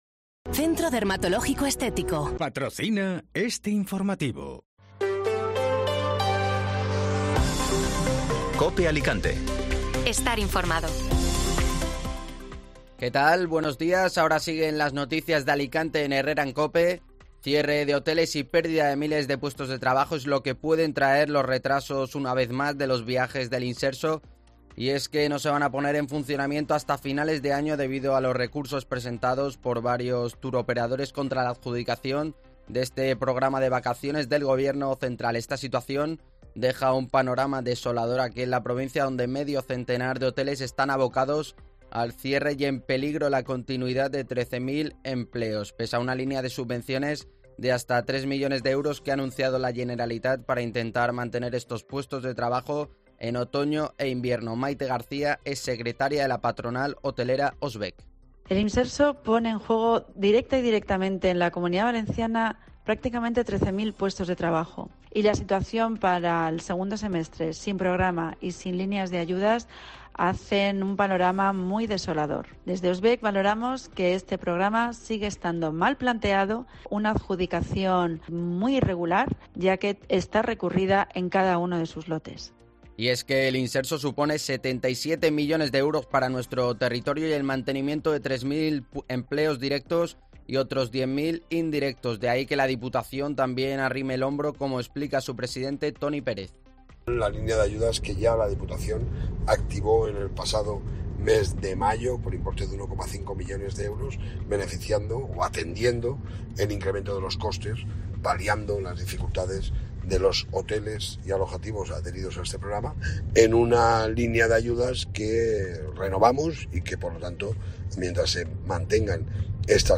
Informativo Matinal (Miércoles 30 de Agosto)